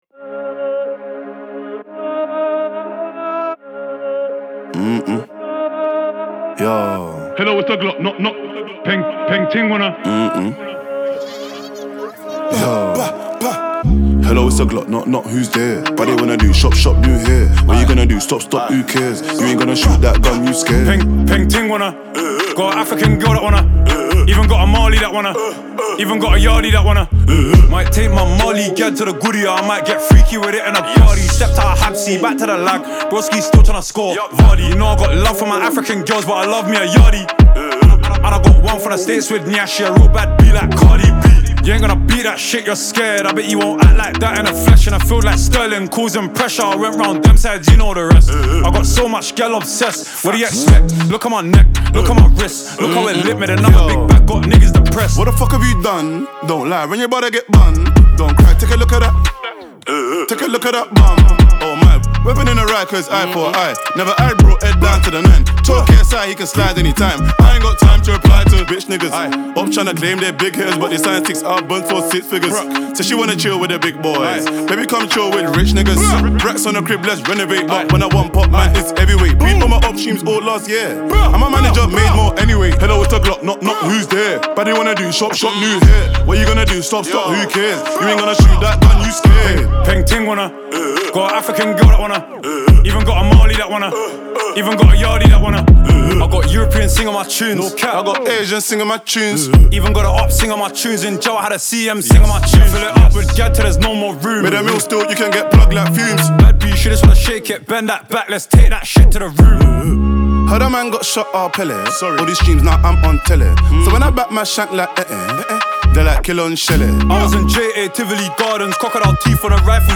United Kingdom rapper and singer
UK-based rapper and musical artist